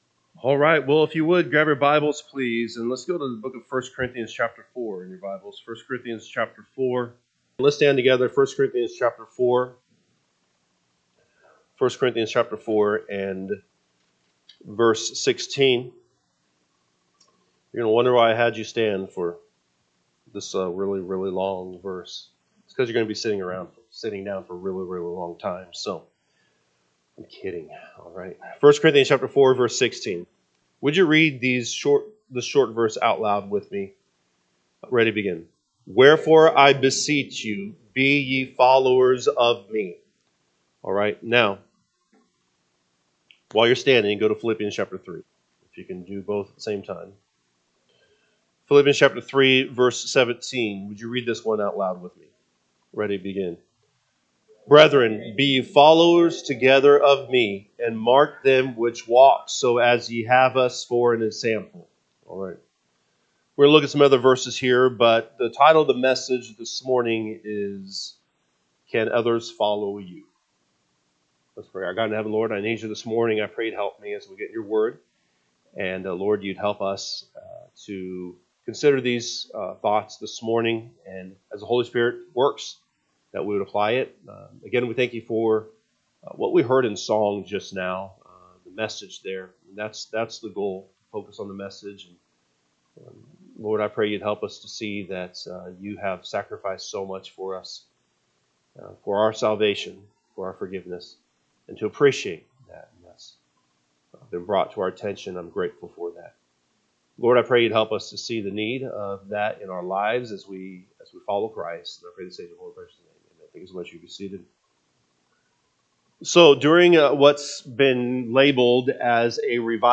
Sunday AM Message